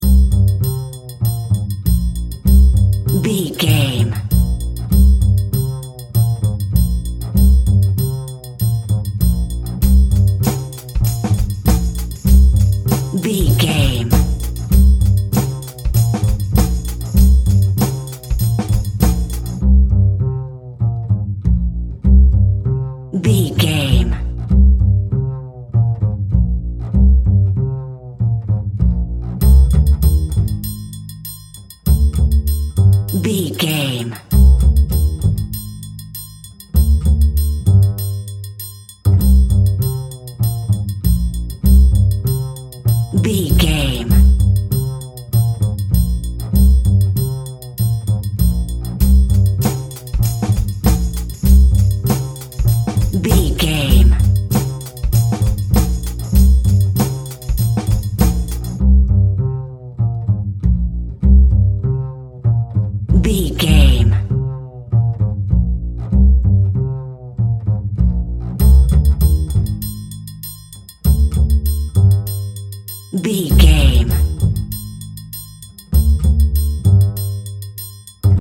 Ionian/Major
Slow
orchestral
dramatic
majestic
cinematic
soaring
synths
synth bass
synth drums